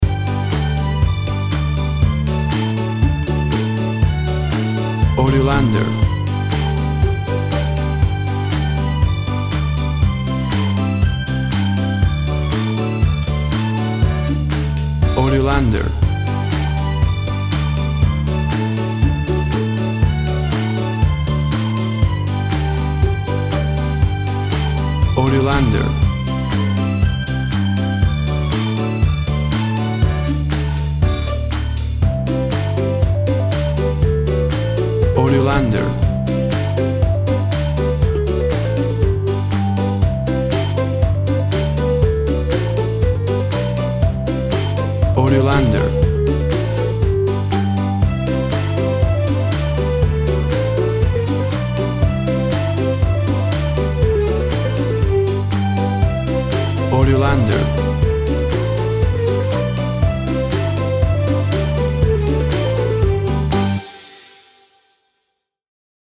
A cute and bubbly piece of kids music.
Upbeat, uptempo and exciting!
Tempo (BPM) 120